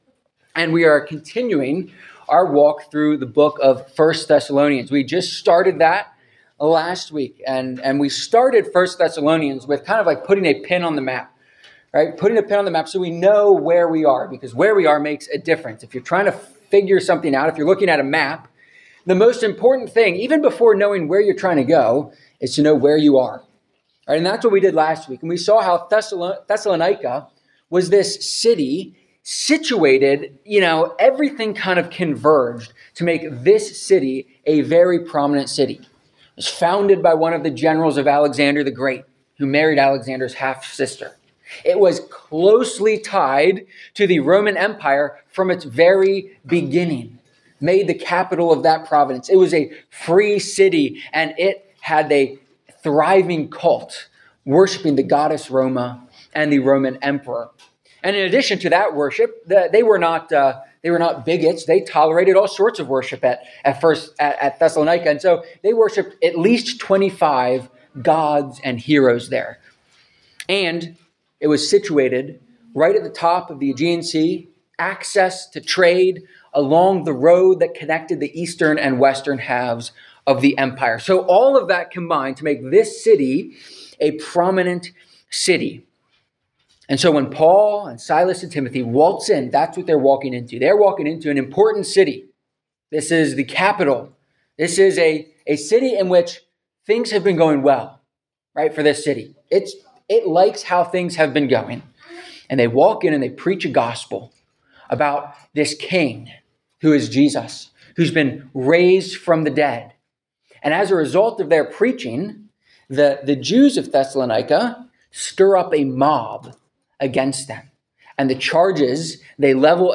1-Thess-1.4-5-sermon.mp3